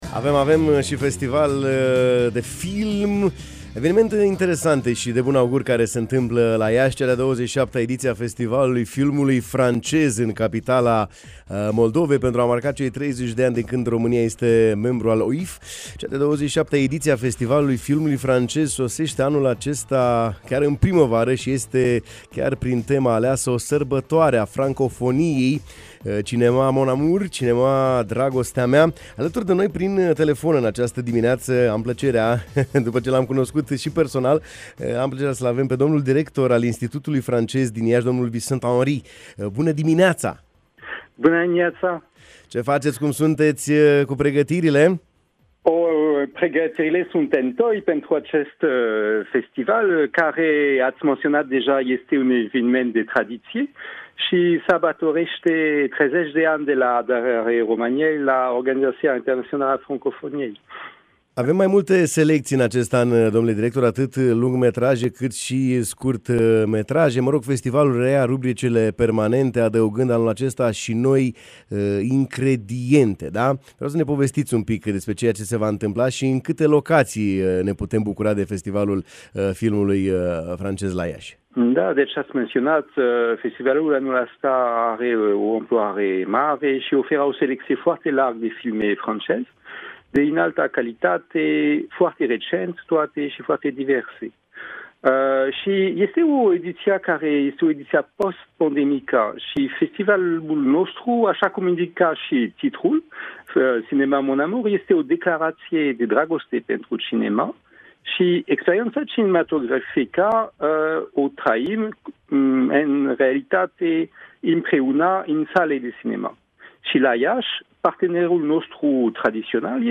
în direct la matinalul de la Radio Iași